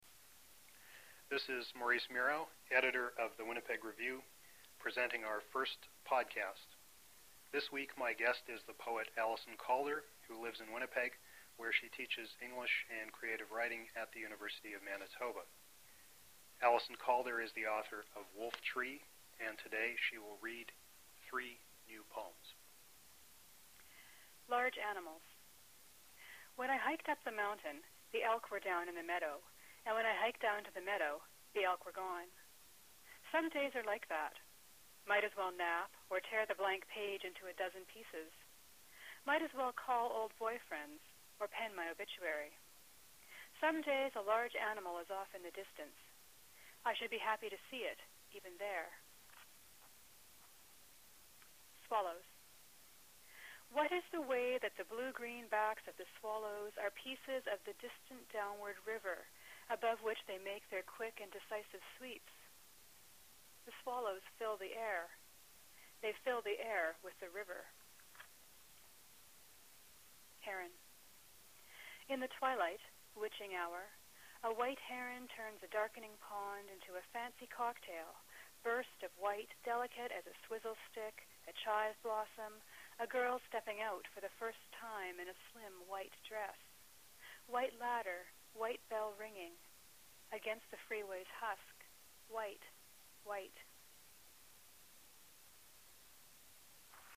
reading her work.